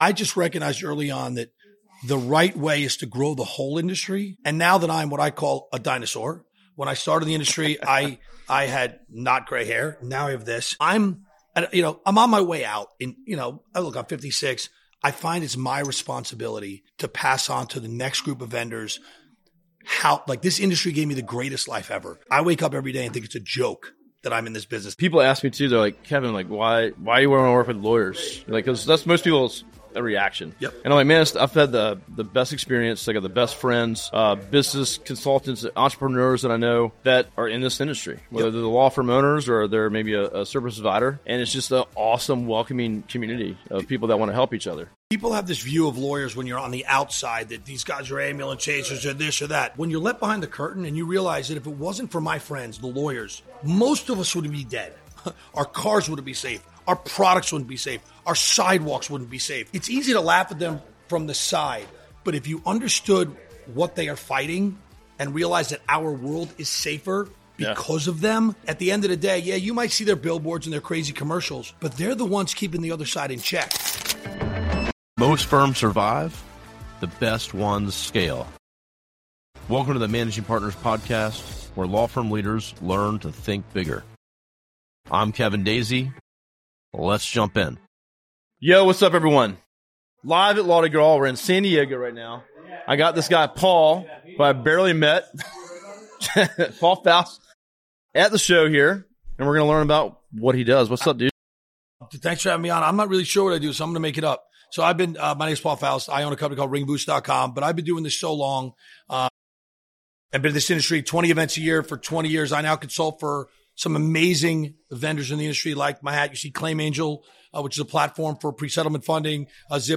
This episode captures the energy of Law-Di-Gras and the power of genuine industry partnerships.